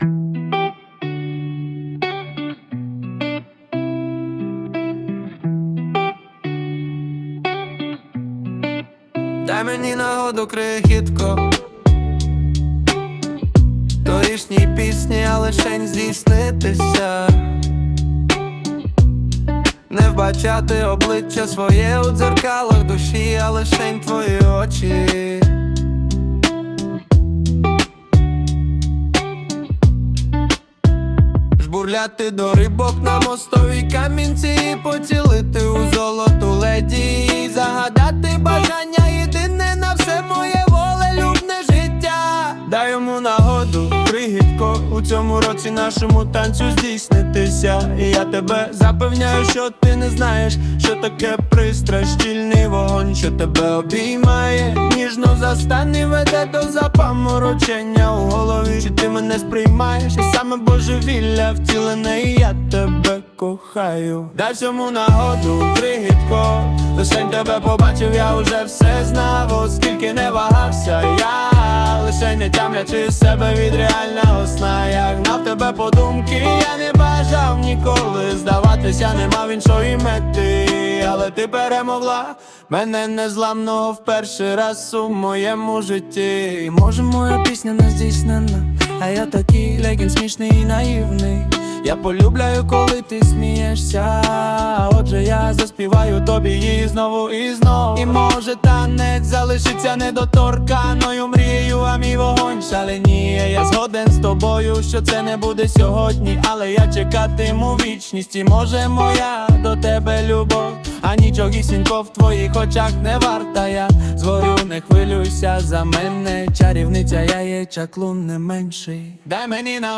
Музыка и исполнение принадлежит ИИ.
ТИП: Пісня
СТИЛЬОВІ ЖАНРИ: Романтичний